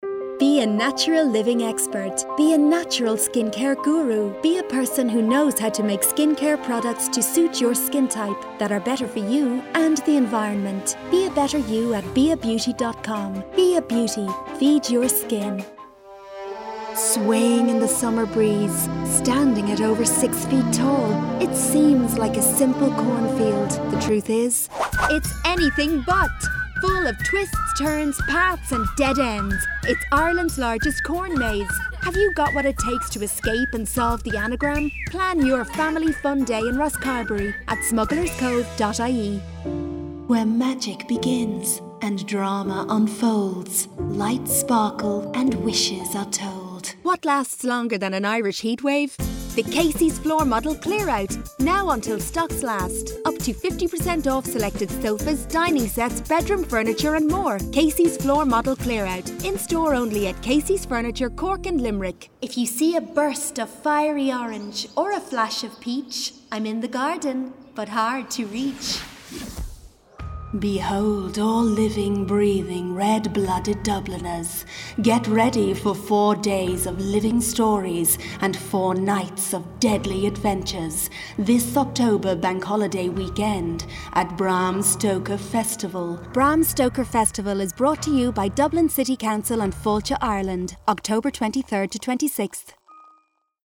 Female
Full broadcast quality home studio
30s/40s, 40s/50s
Irish Dublin Neutral, Irish Neutral